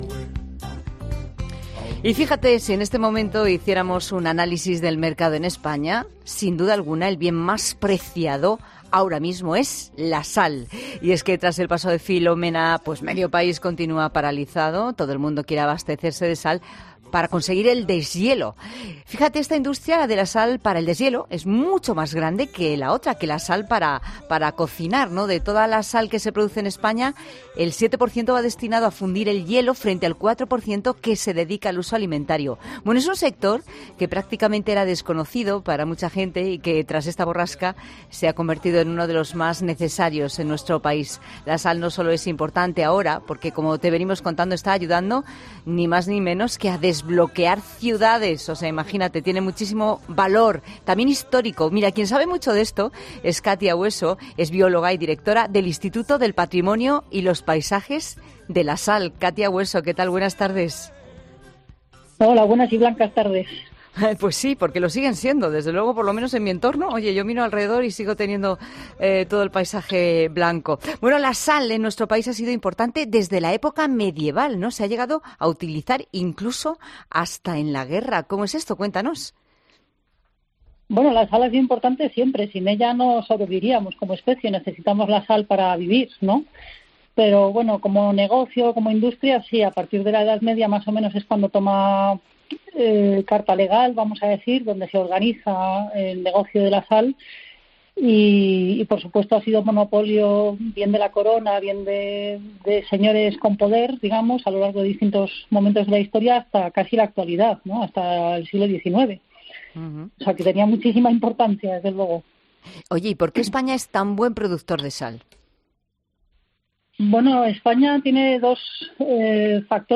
En 'La Tarde' hemos hablado con una experta que nos ha explicado las propiedades de este producto y los motivos por los que se ha convertido en un bien indispensable